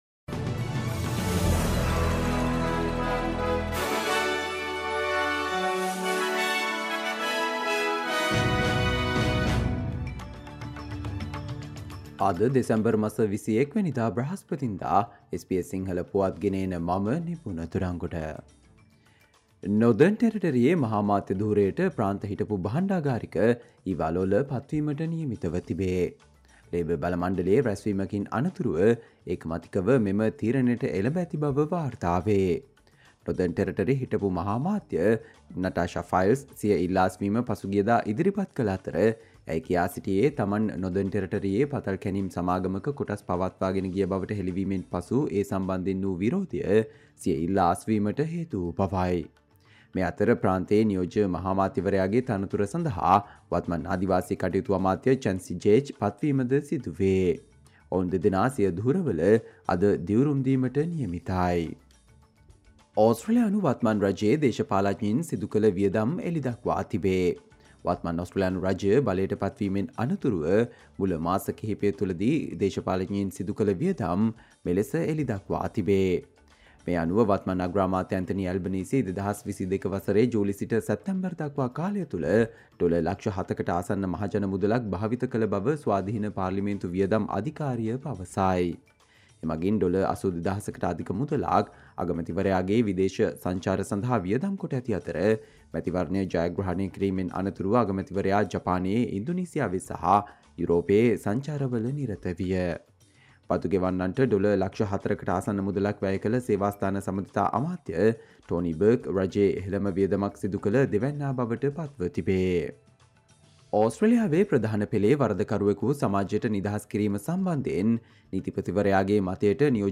Australia news in Sinhala, foreign and sports news in brief - listen, Thursday 21 December 2023 SBS Sinhala Radio News Flash